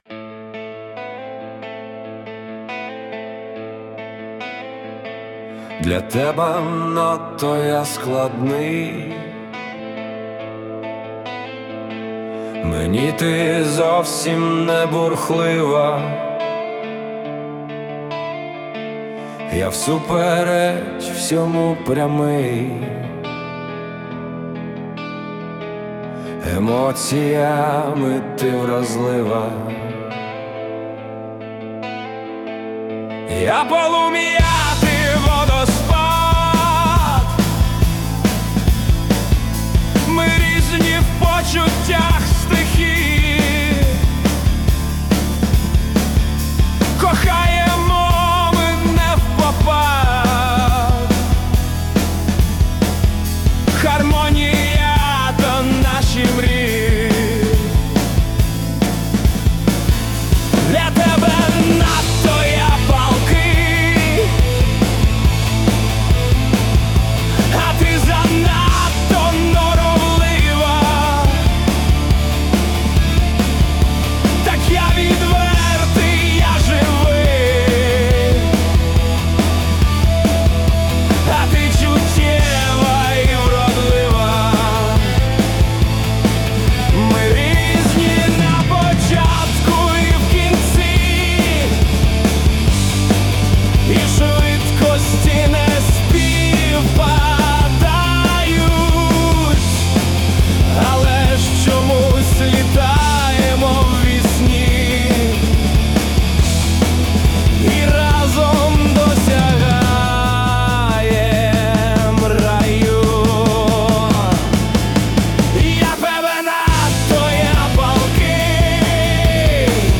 Я - полум'я, ти - водоспад (rock) (пісня)
Музика і вокал ШІ - SUNO AI
СТИЛЬОВІ ЖАНРИ: Ліричний
ВИД ТВОРУ: Пісня